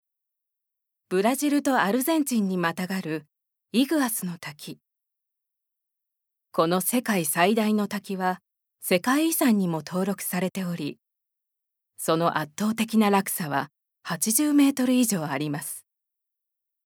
ボイスサンプル
ナレーション４